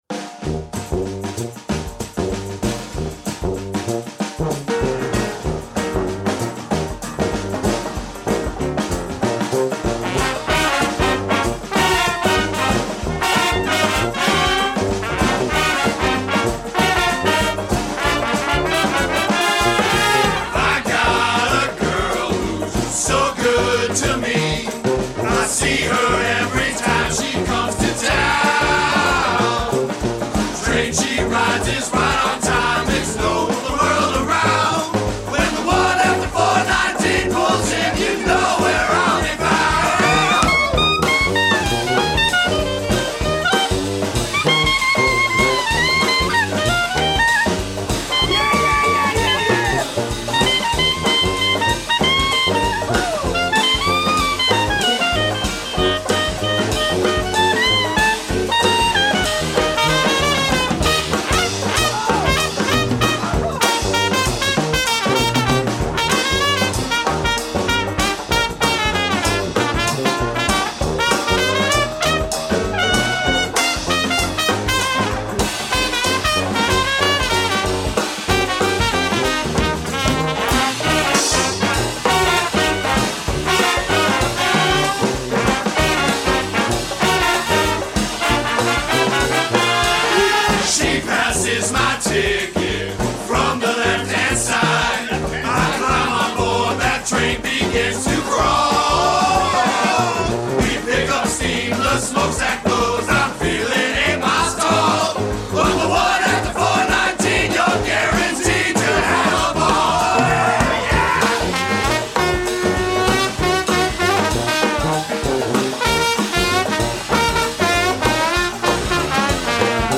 Trumpet.
Trombone, Synthesizer, Whistle.
Clarinet.
Baritone Ukulele.
Tuba.
Drums, Congas, Shaker, Cowbell, Frying Pan.